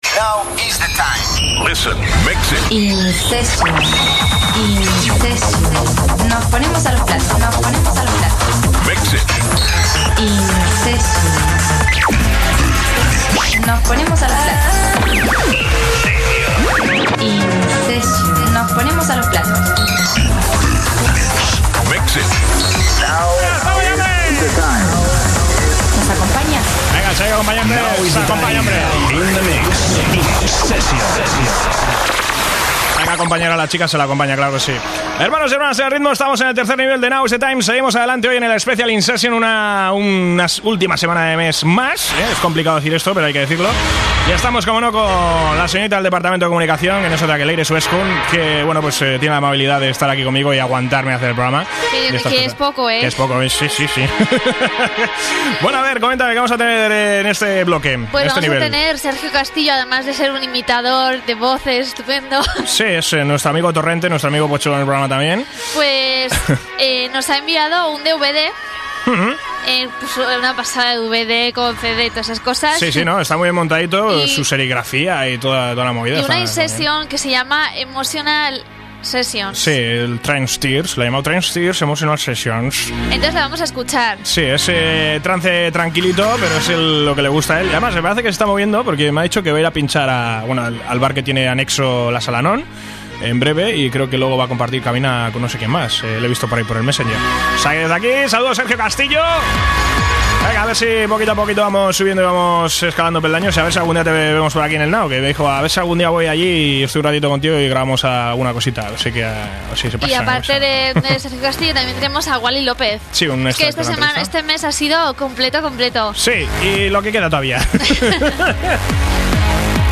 SESION TRANCE